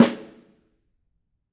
Snare2-HitSN_v9_rr2_Sum.wav